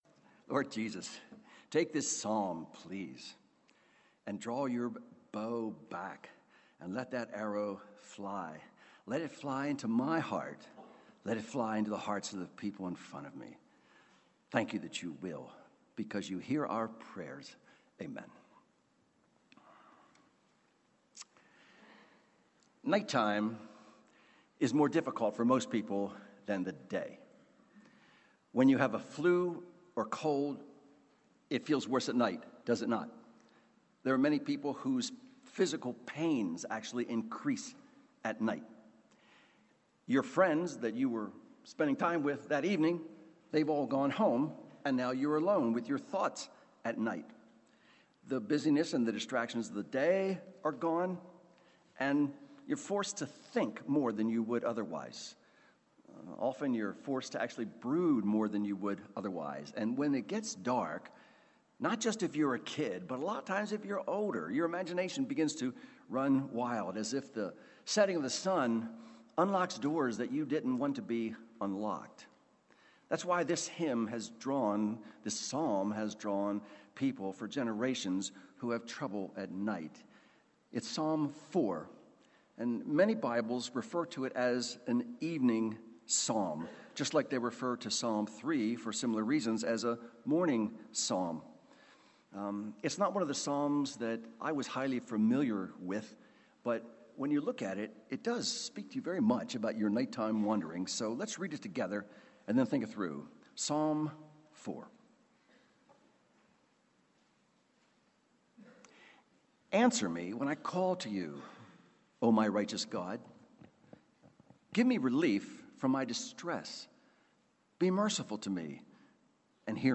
Sermons on Psalm 4 — Audio Sermons — Brick Lane Community Church